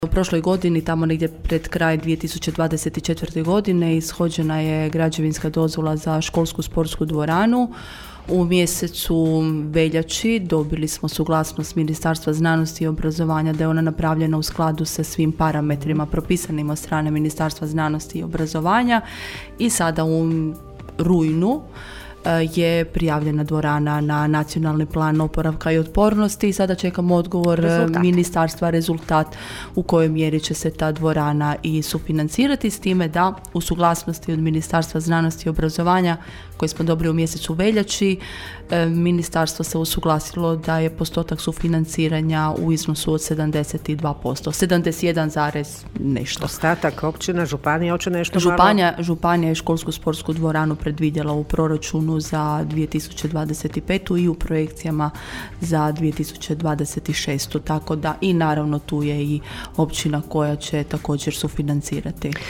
Načelnica općine Irene Franković posebno ističe projekt izgradnje školsko-sportske dvorane: (